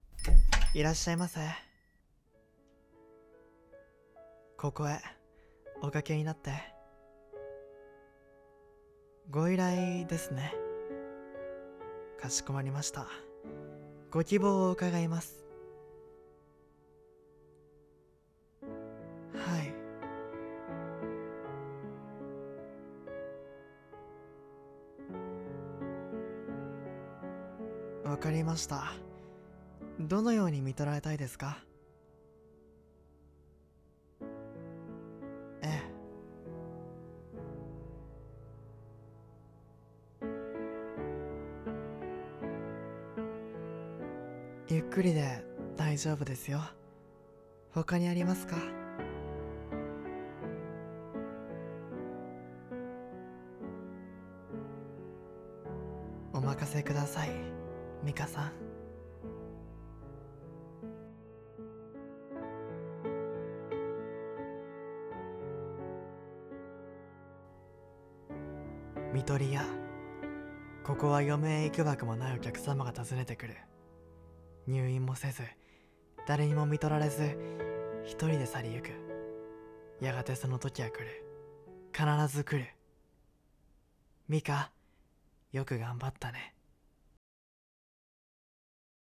看取り屋 【掛け合い 台本 声劇】